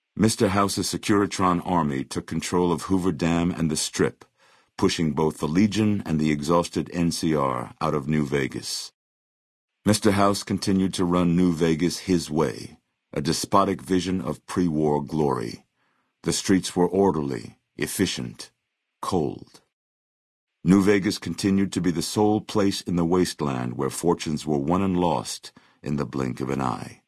Category:Fallout: New Vegas endgame narrations Du kannst diese Datei nicht überschreiben.